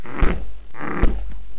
mummy_attack10.wav